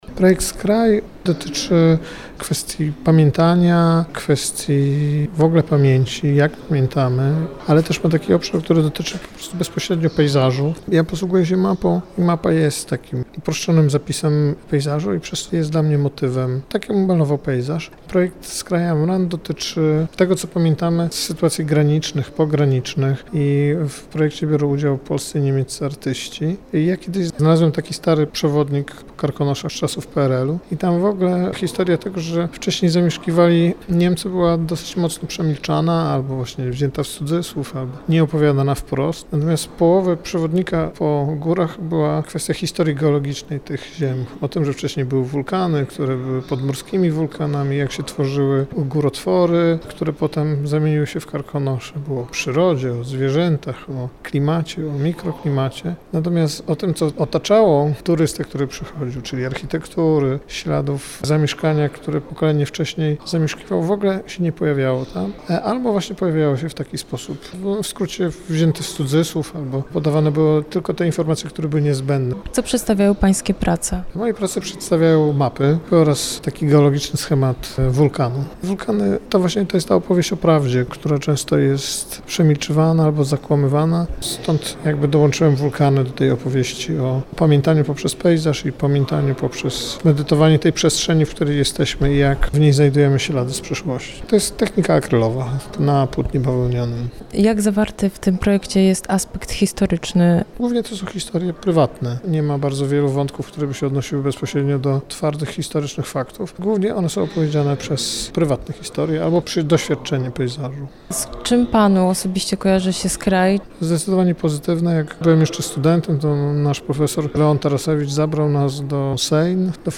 Wystawa olsztyńska została rozszerzona o wątki historii Warmii i kontemplacji pamięci tego regionu. Posłuchajcie relacji z wernisażu w Kortowie.